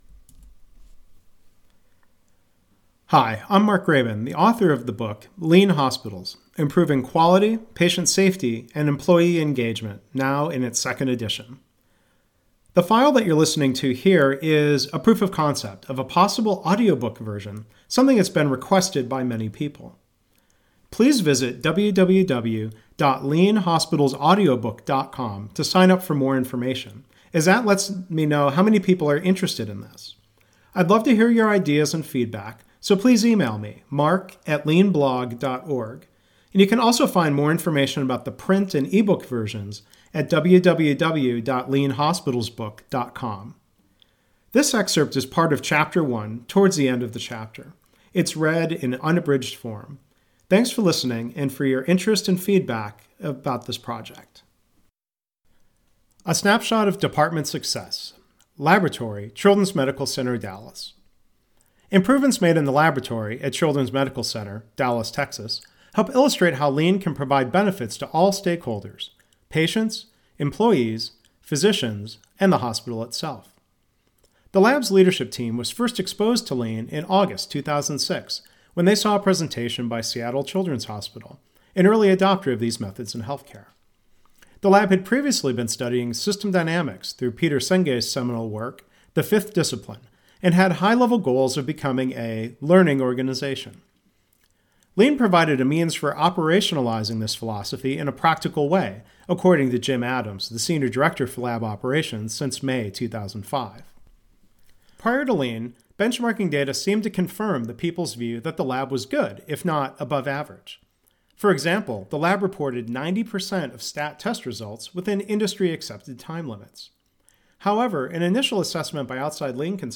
There are now two “proof of concept” audio files that I’ve recorded: